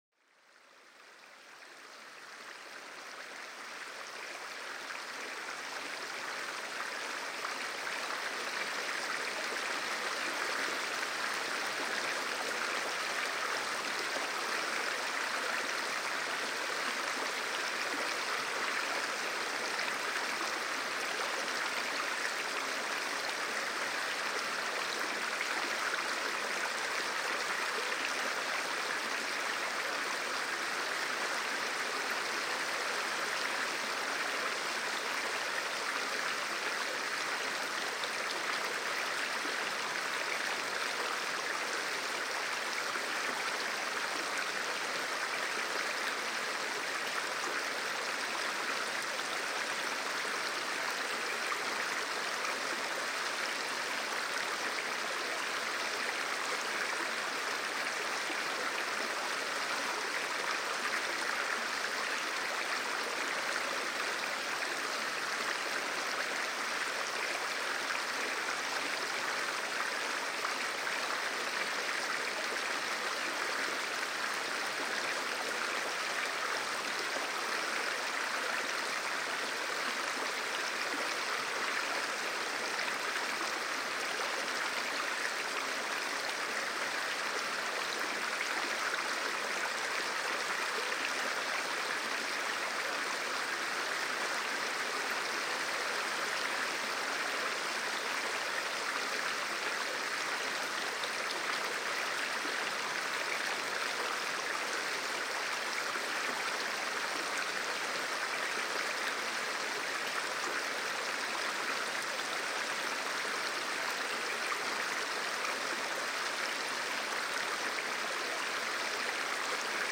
Le murmure apaisant d’une rivière pour calmer l’esprit
Plongez dans la douceur des flots avec le son apaisant d’une rivière qui s’écoule paisiblement. Chaque goutte et chaque courant vous invite à relâcher la tension et à vous connecter à la sérénité de la nature.